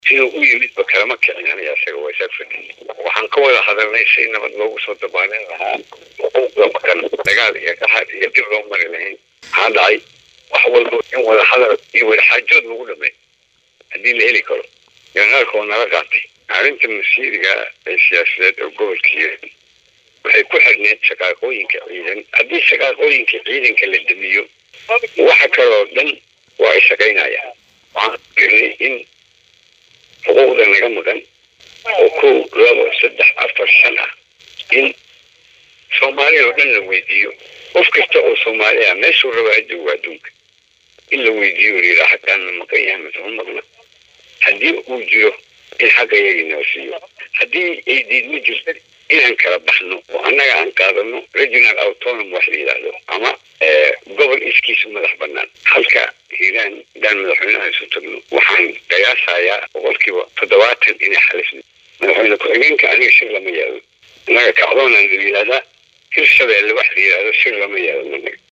DHAGEYSO:Janeraal Xuud oo wareysi uu bixiyay ku sheegay in uu Muqdisho tagaya